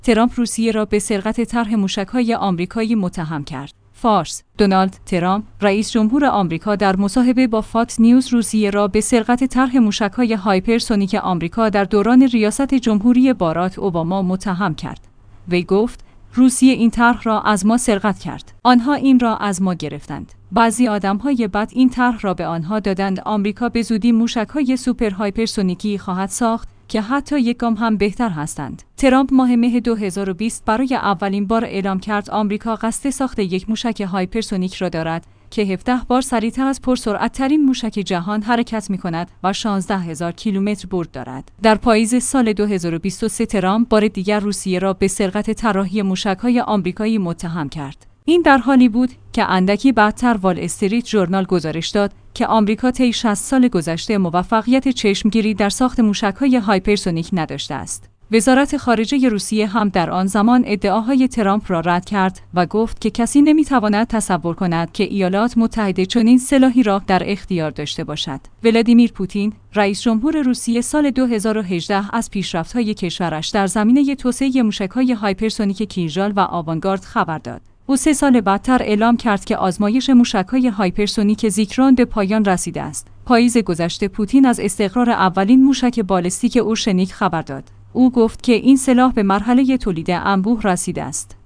فارس/ دونالد ترامپ، رئیس‌جمهور آمریکا در مصاحبه با فاکس‌نیوز روسیه را به سرقت طرح موشک‌های هایپرسونیک آمریکا در دوران ریاست‌جمهوری باراک اوباما متهم کرد.